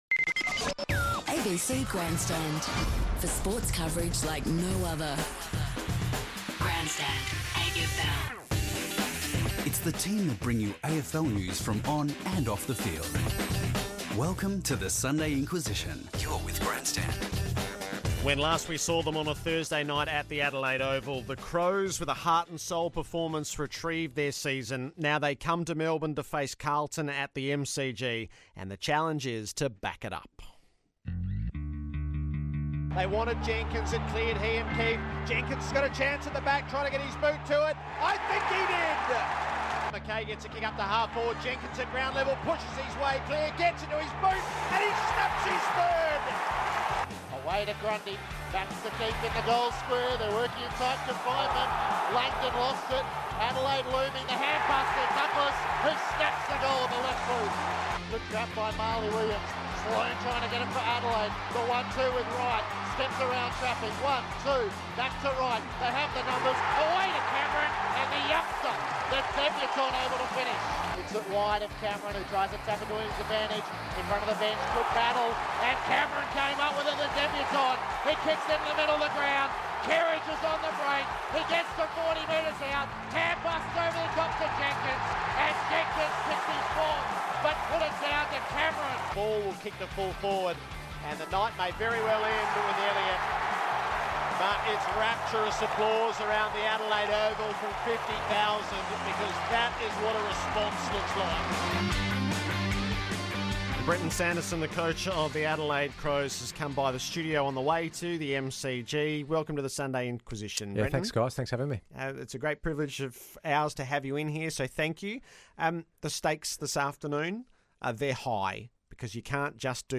Brenton Sanderson sat down with the ABC Grandstand team for an in-depth interview on the current crop of Crows, the challenges of modern football, and life beyond the coaches box.